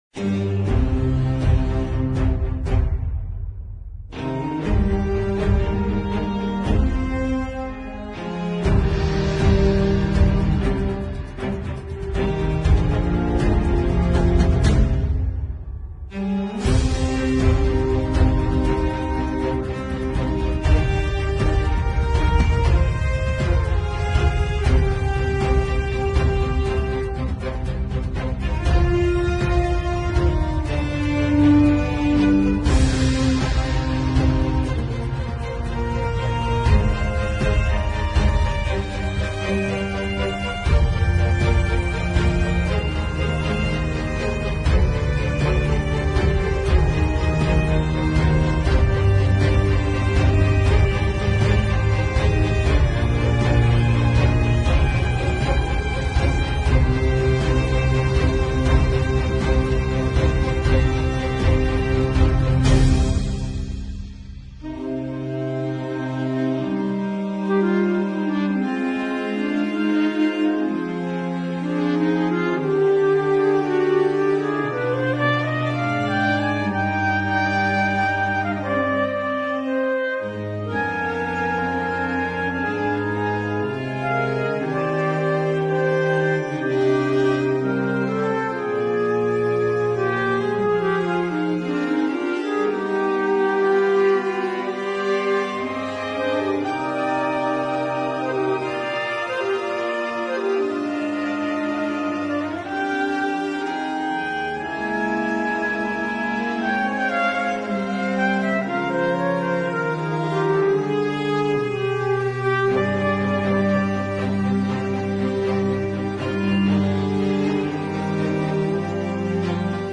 Instrumental Para Ouvir: Clik na Musica.